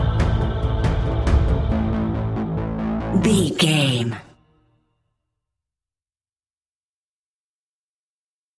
In-crescendo
Thriller
Aeolian/Minor
scary
ominous
haunting
eerie
horror music
Horror Pads
horror piano
Horror Synths